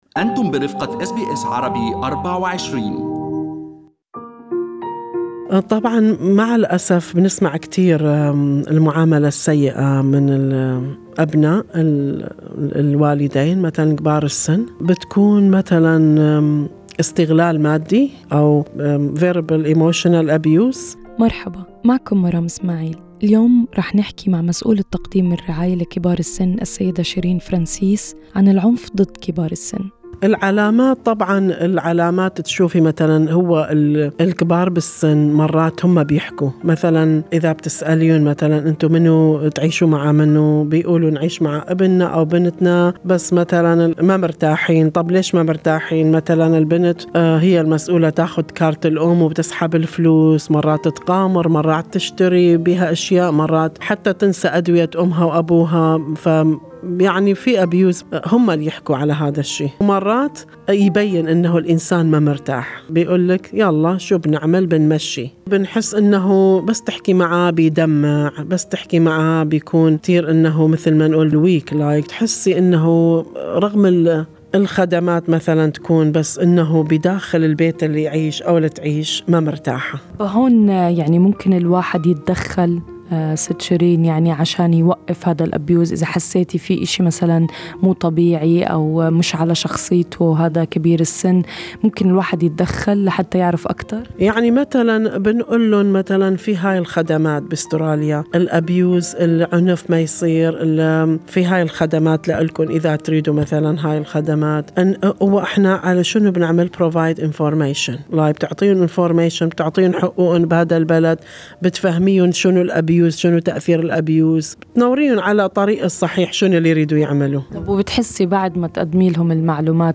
وستستمعون في كل حلقة الى بعض من قصص كبار السن وتجاربهم مع الخدمات التي يستفيدون منها في استراليا لتحسن من نوعية الحياة التي يعيشونها بالإضافة الى بعض من النصائح المقدمة من مسؤولي تقديم الرعاية للمسنين.